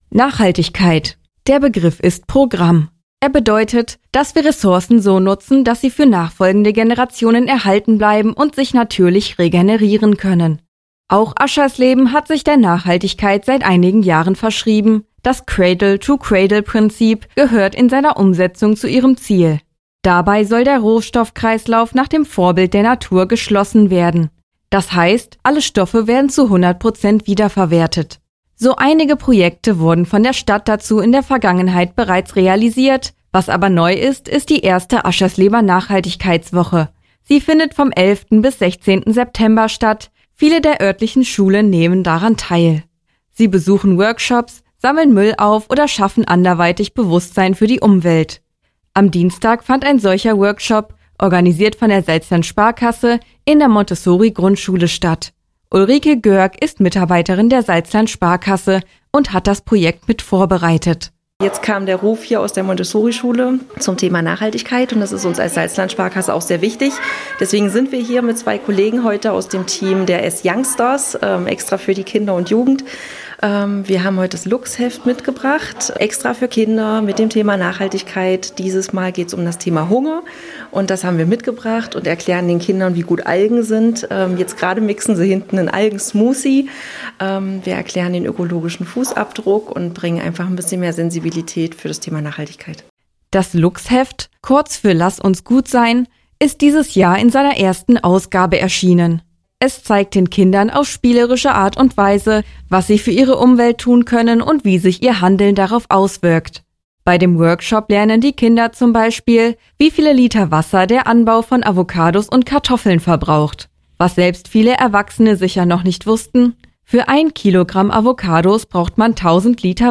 Hörbeitrag vom 14. September 2023